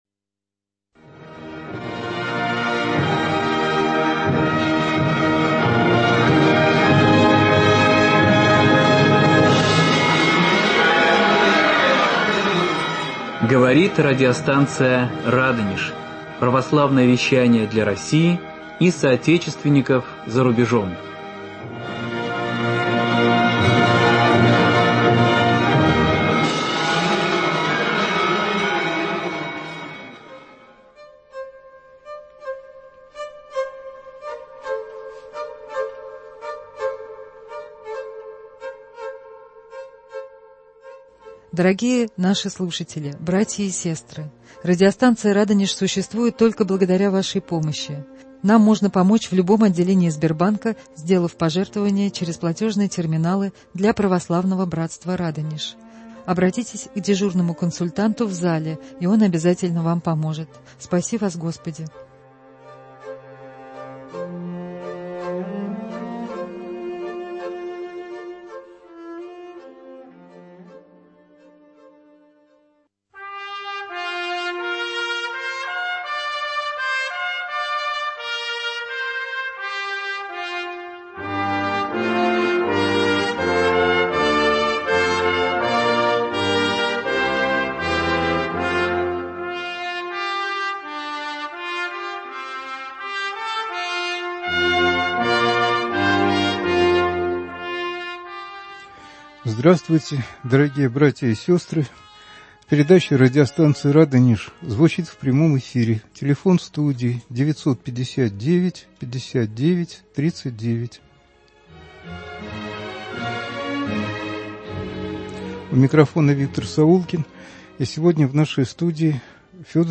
Гость - историк